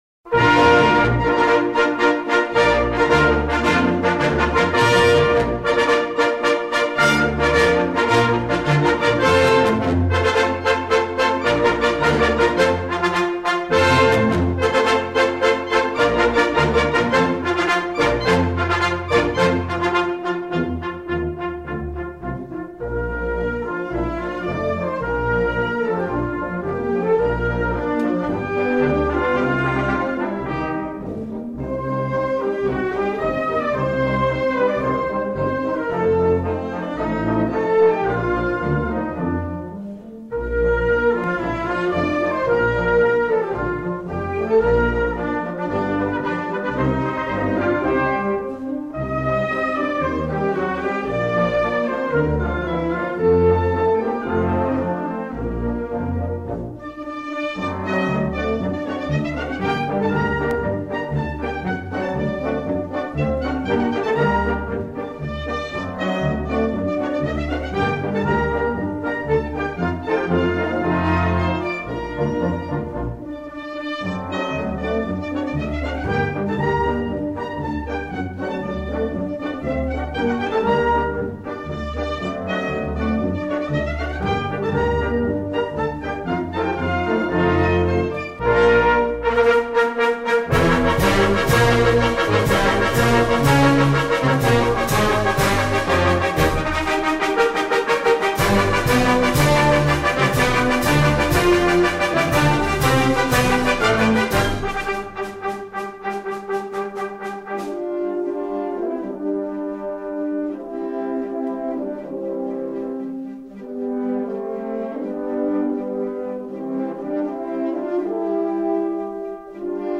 Gattung: Ouvertüre
A4 Besetzung: Blasorchester PDF